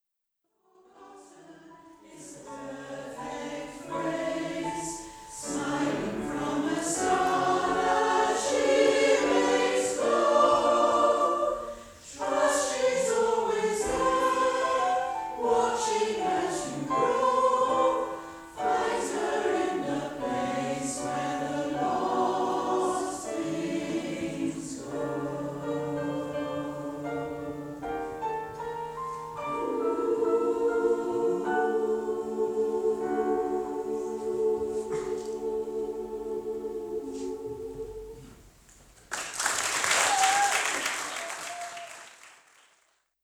We are one of the largest mixed gender choirs in the area
A selection of audio excerpts from various performances by the Barry Community Choir, recorded at venues across South Wales.
Place-Where-Lost-Things-Go-LIVE.wav